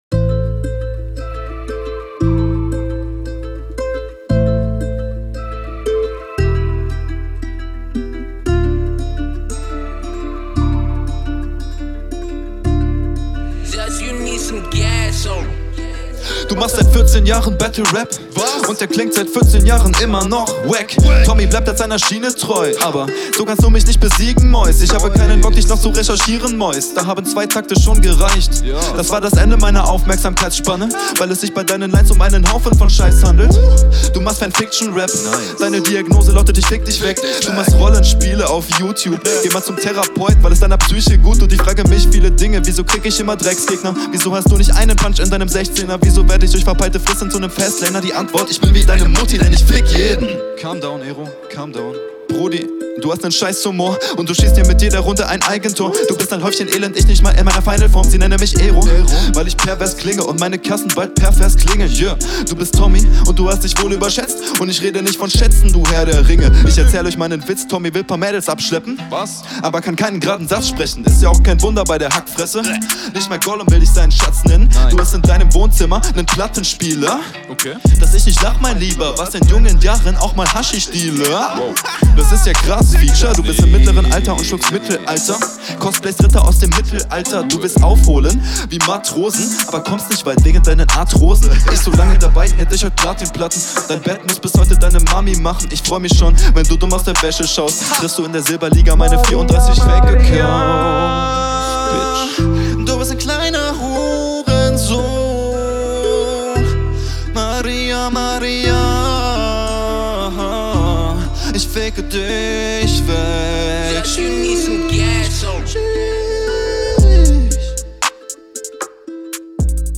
Auf diesem Beat kommst du deutlich besser.
die shuffles in deinem flow sind zt cool und zt iwie strange hahaha. aber nichts …